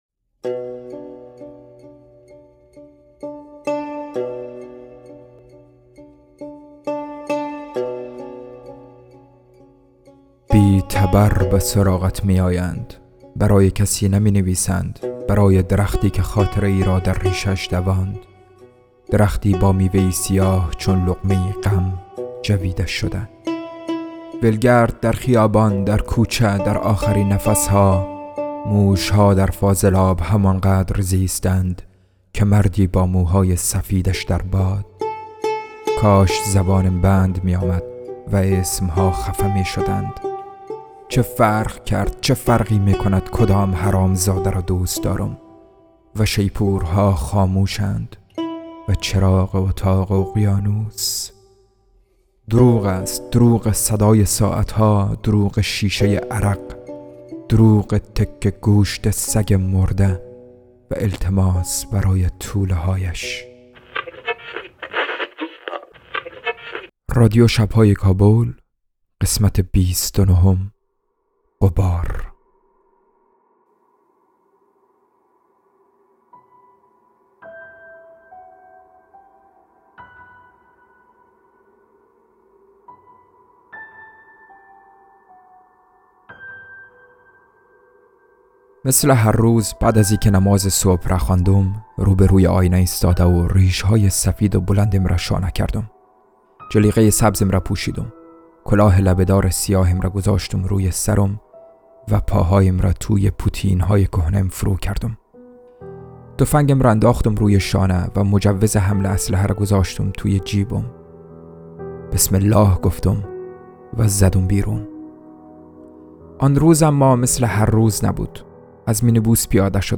پادکست شب های کابل یک داستان کوتاه، چند شعر و چند موسیقی مهمان ما باشید - تهران پادکست